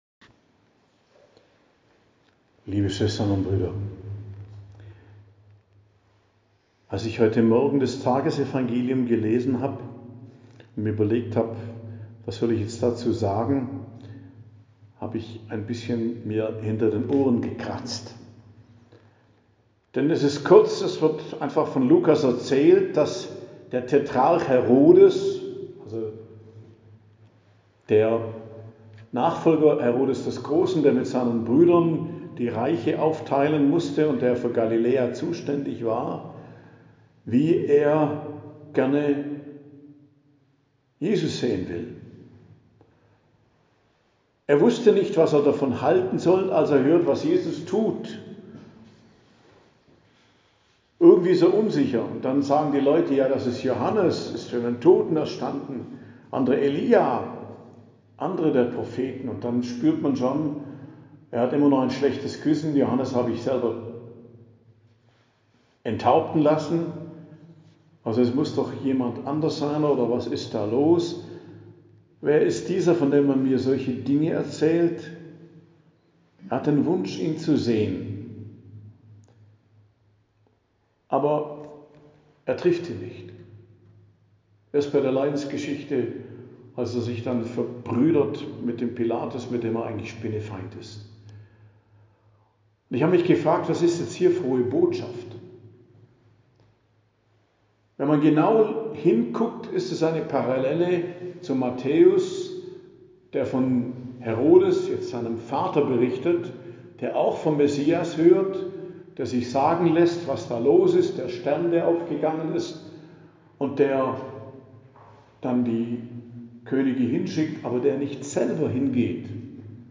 Predigt am Donnerstag der 25. Woche i.J., 25.09.2025 ~ Geistliches Zentrum Kloster Heiligkreuztal Podcast